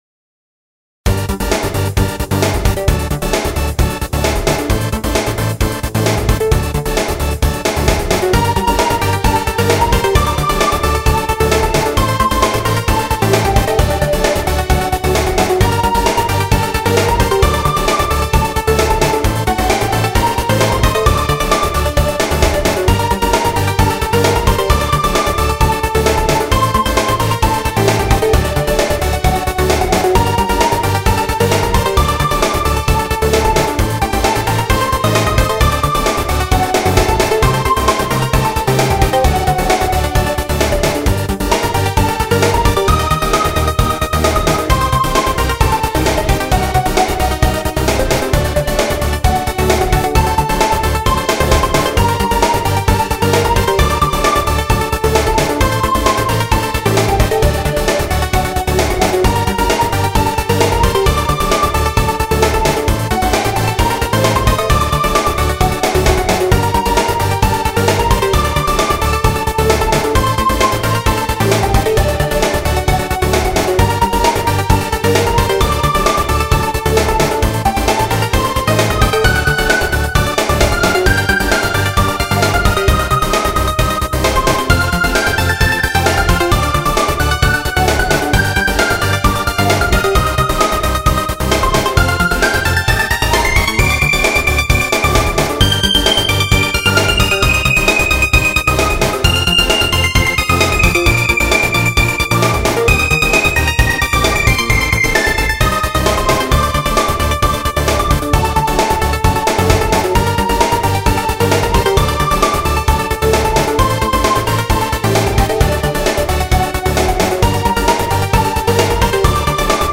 BGM
EDMアップテンポ激しい